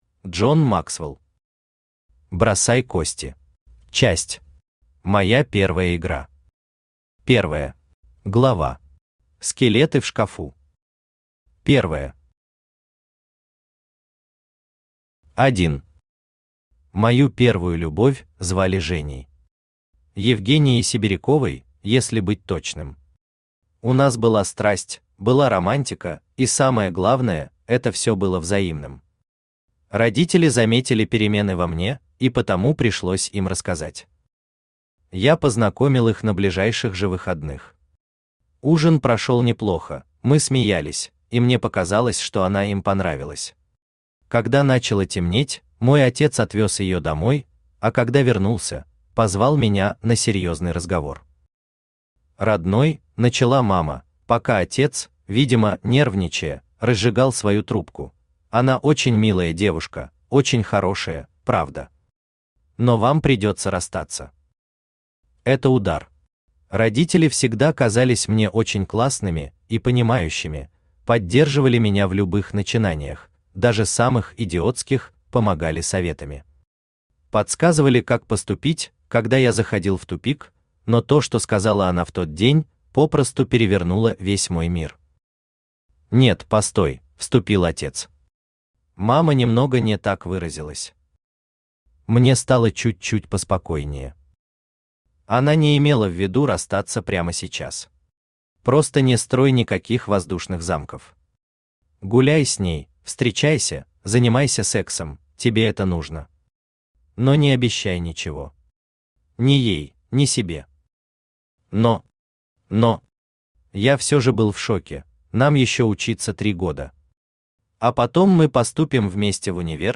Аудиокнига Бросай кости | Библиотека аудиокниг
Aудиокнига Бросай кости Автор Джон Максвелл Читает аудиокнигу Авточтец ЛитРес.